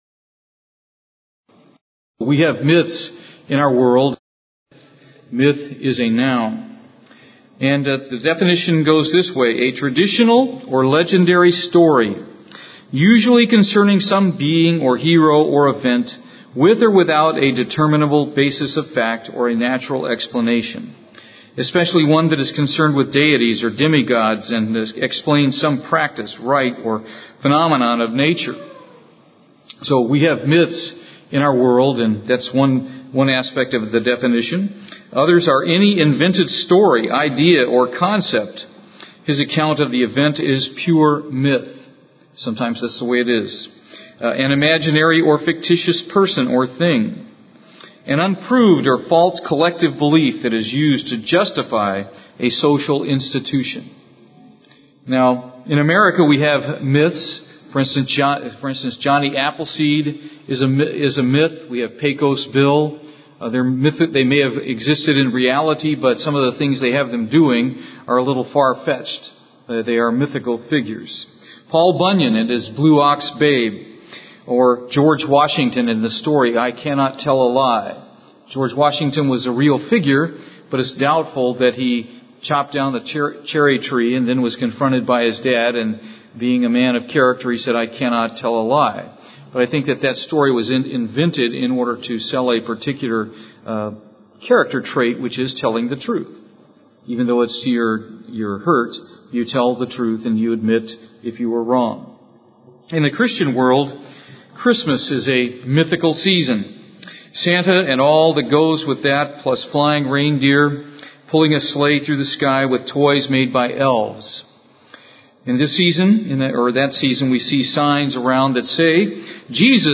Given in Houston, TX
UCG Sermon Studying the bible?